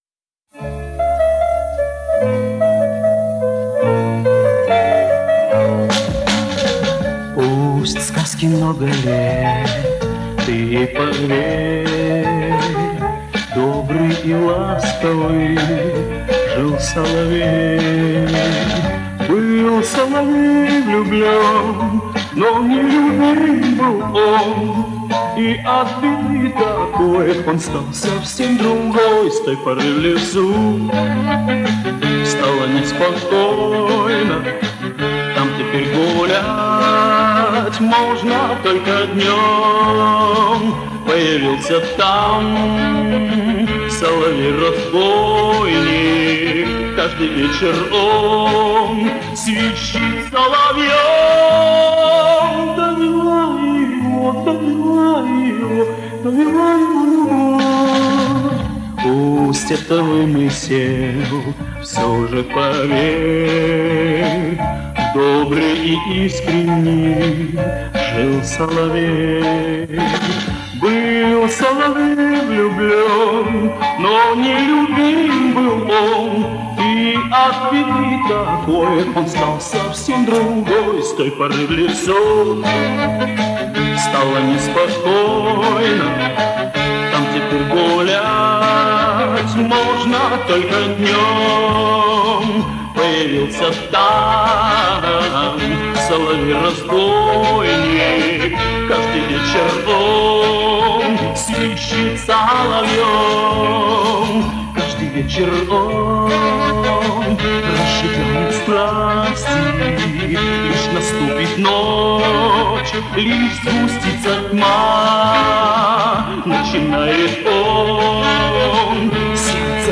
поет под большой оркестр